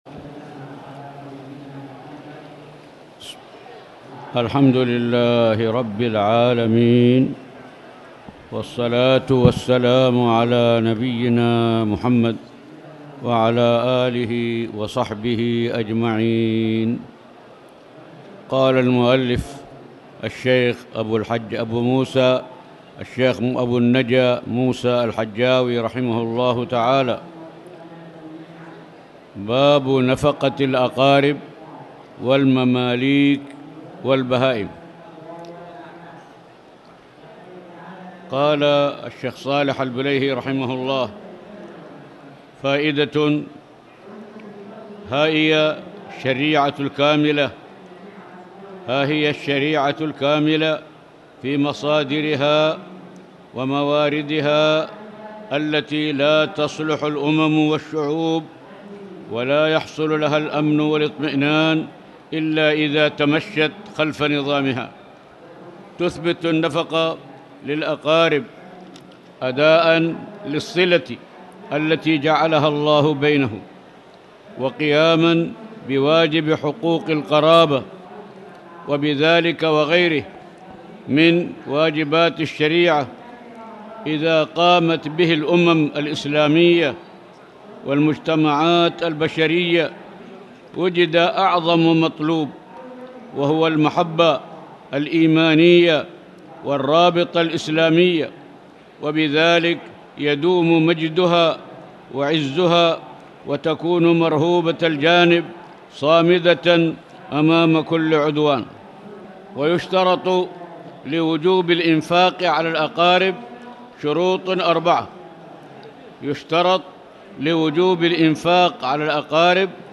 تاريخ النشر ١٠ محرم ١٤٣٨ هـ المكان: المسجد الحرام الشيخ